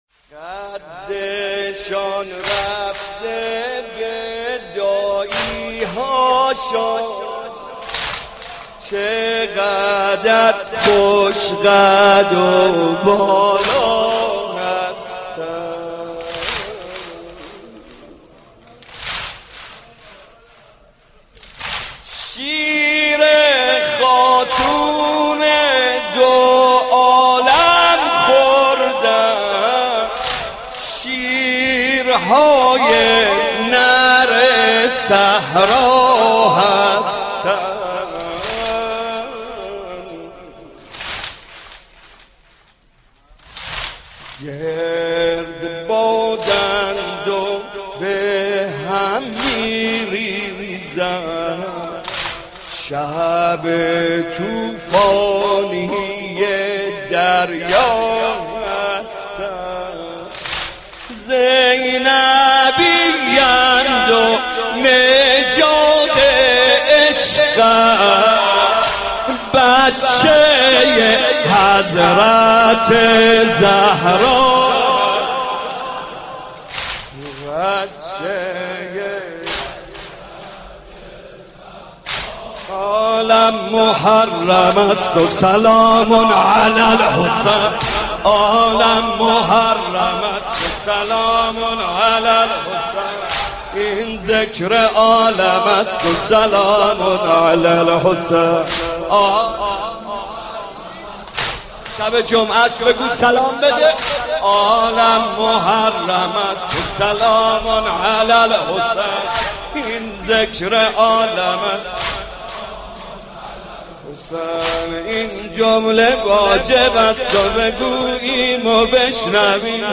مجلس سینه زنی؛ «قدشان رفته به دایی هایشان، چقدر خوش قد و بالا هستند» با مداحی «محمدرضا طاهری»